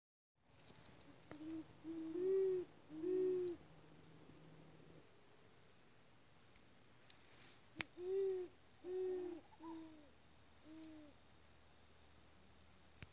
Jacurutu (Bubo virginianus)
tres ejemplares se acercaron cuando los llame
Nome em Inglês: Great Horned Owl
Localidade ou área protegida: Las Varillas
Condição: Selvagem
Certeza: Observado, Gravado Vocal
nacurutu.mp3